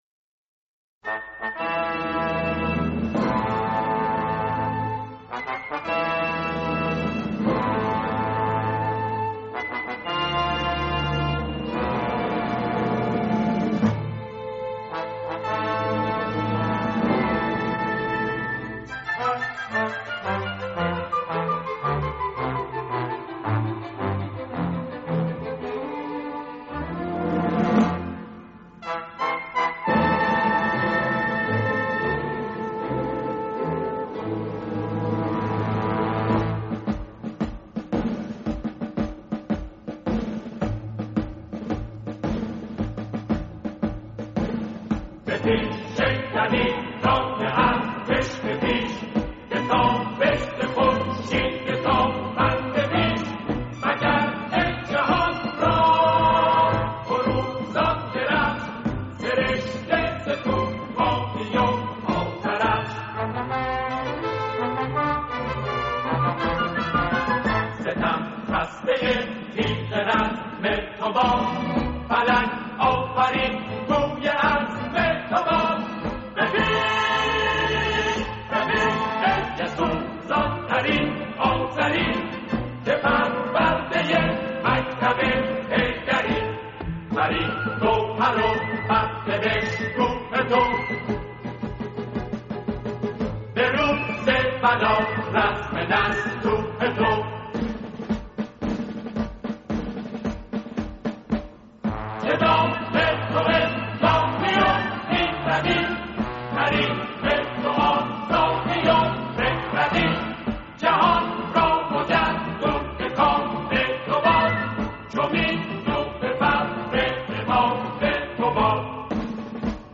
سرود در ستایش ارتش